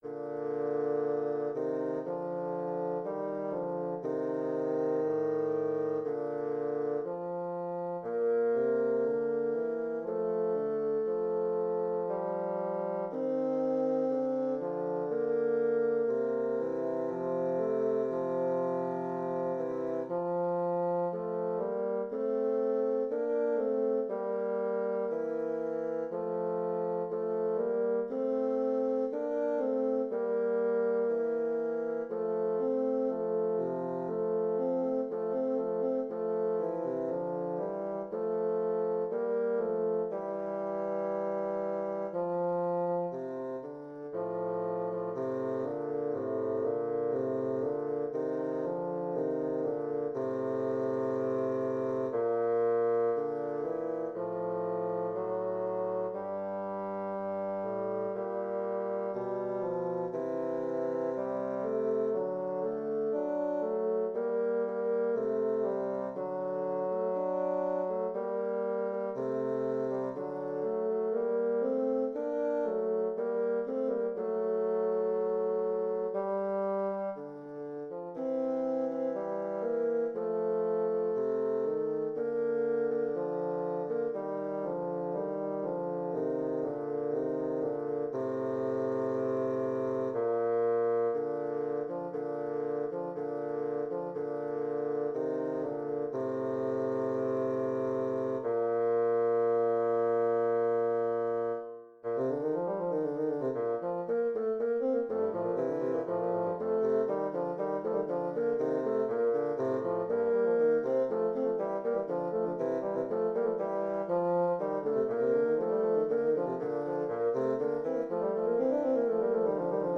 Intermediate bassoon duet
Instrumentation: Bassoon duet